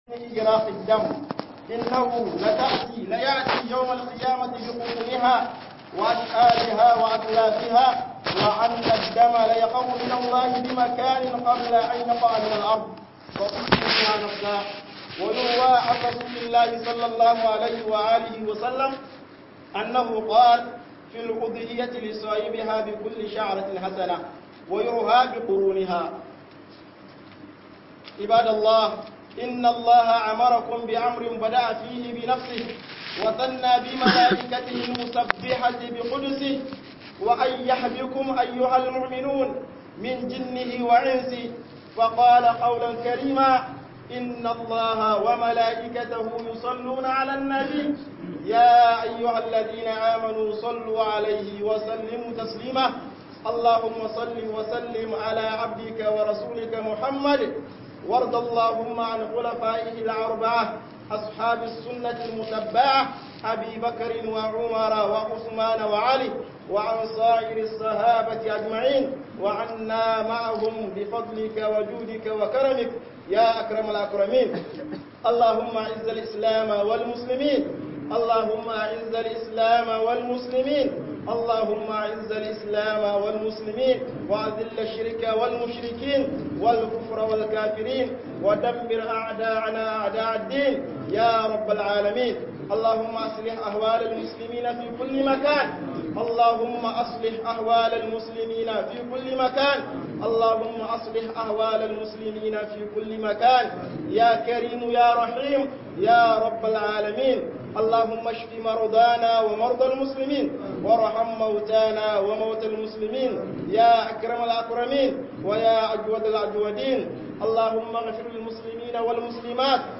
HUƊUBOBIN JUMA'A